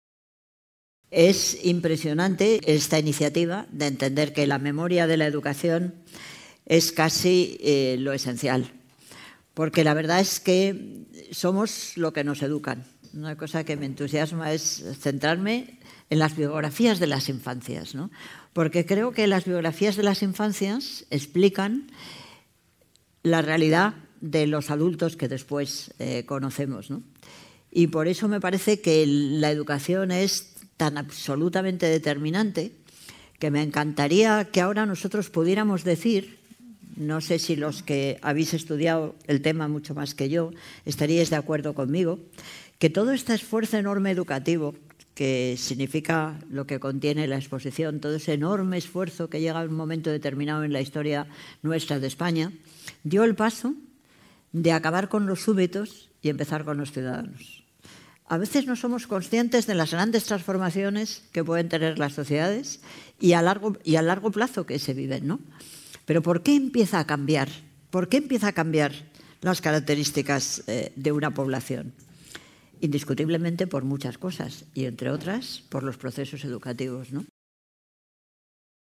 Coloquio entre Manuela Carmena y Almudena Grandes